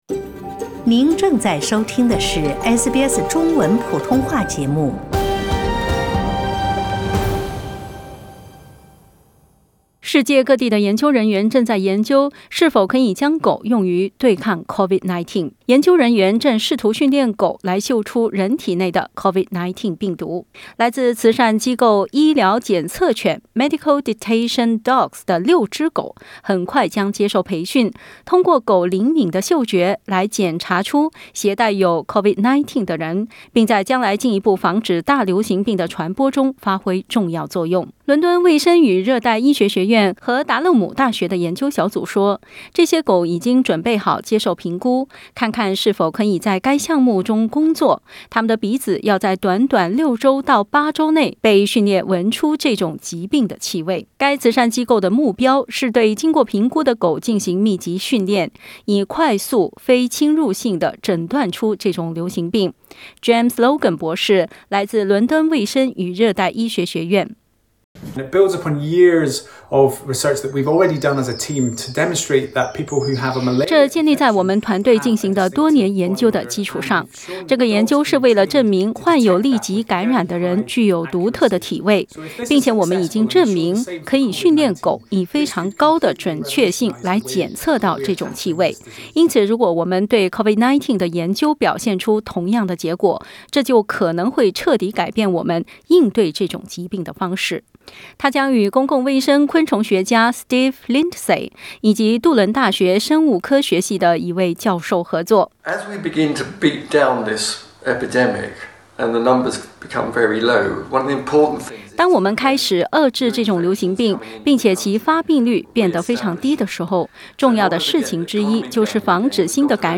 研究人员试图训练狗来嗅出人体内的新冠病毒。 来自慈善机构Medical Detection Dogs的六只狗将很快接受培训，通过狗灵敏的嗅觉，来检查出携带有新冠病毒的人。 点击图片收听详细报道。